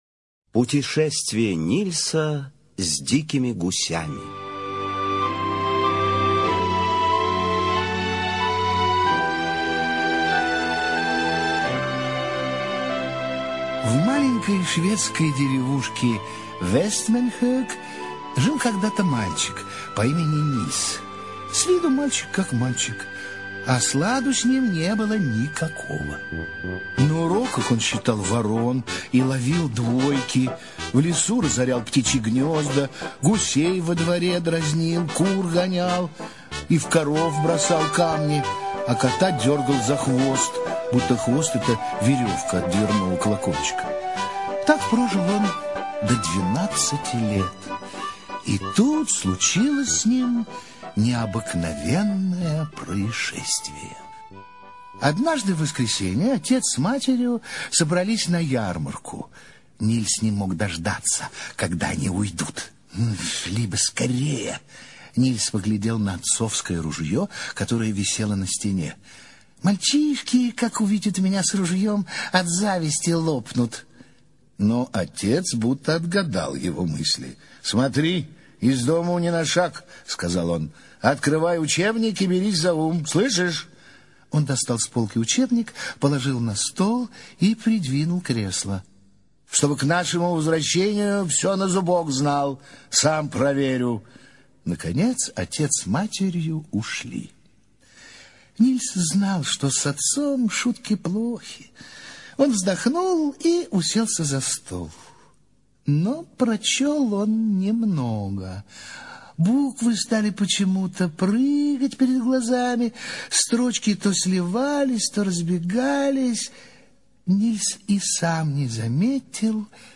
Путешествие Нильса с дикими гусями - аудиосказка - слушать